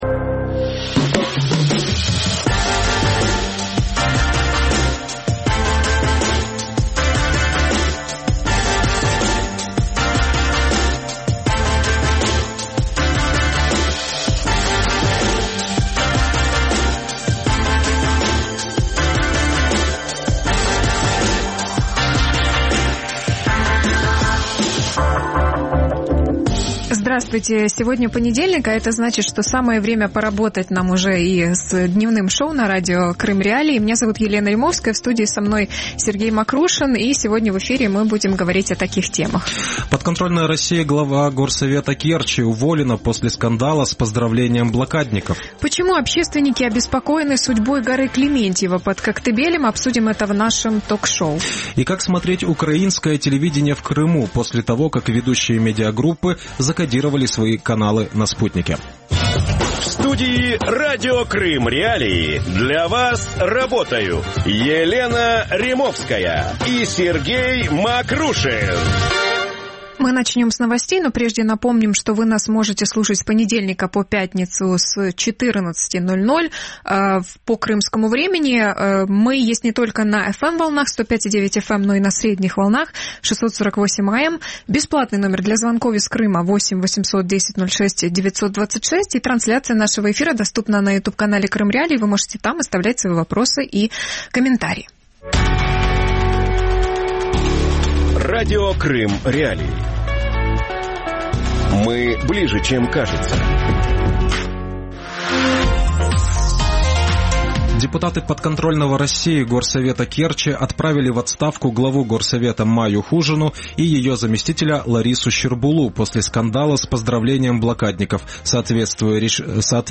Коктебель: бульдозеры на горе Клементьева | Дневное ток-шоу